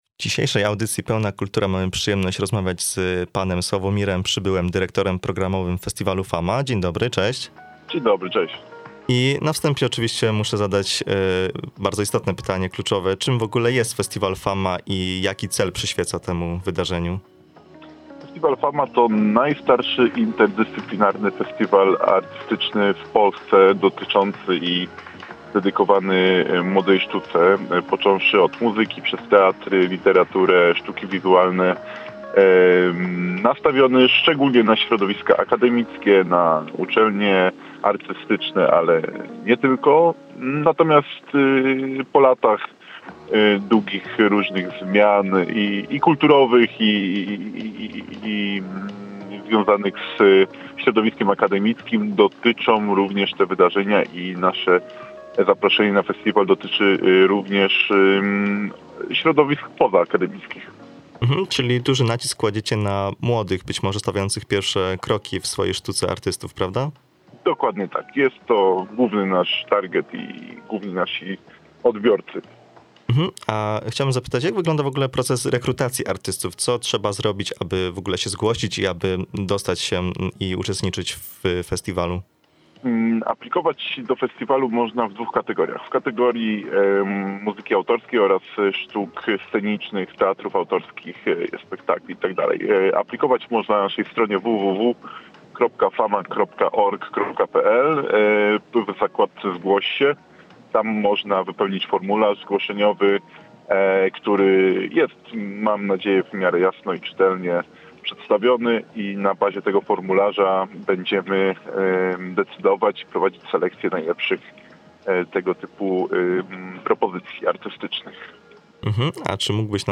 rozmawiał